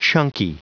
Prononciation du mot chunky en anglais (fichier audio)
Prononciation du mot : chunky